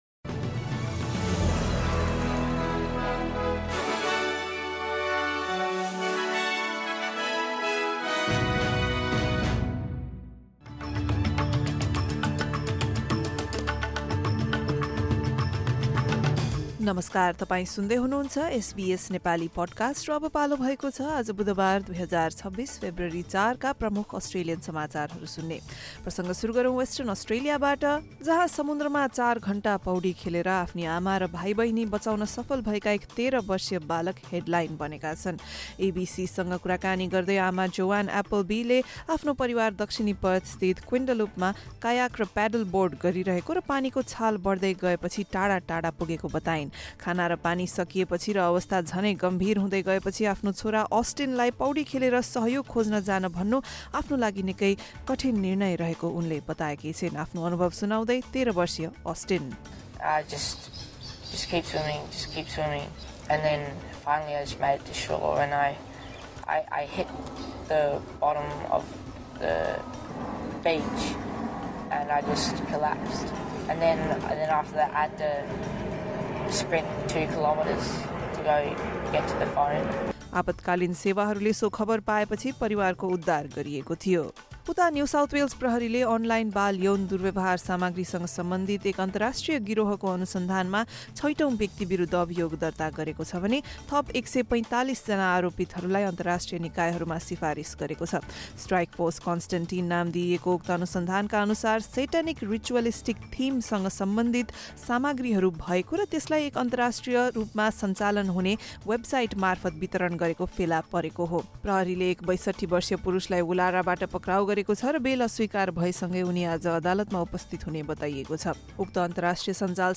SBS Nepali Australian News Headlines: Wednesday, 4 February 2026